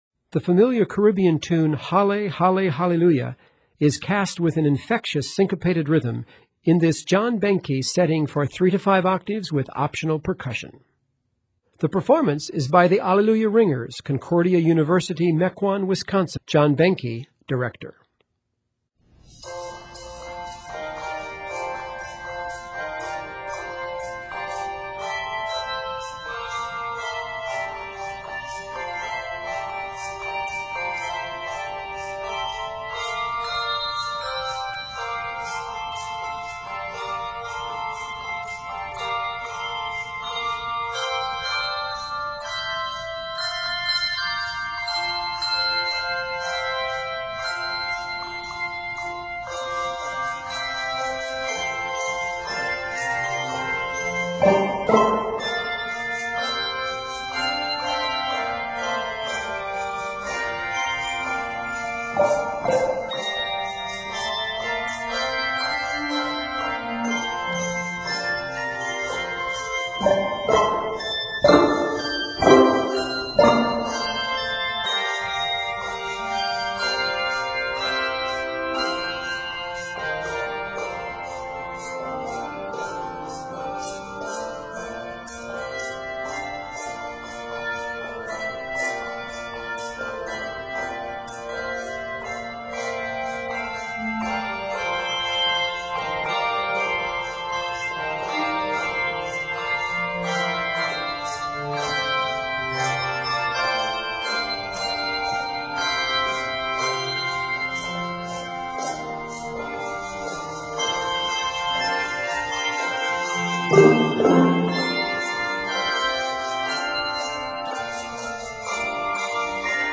is cast with an infectious syncopated rhythm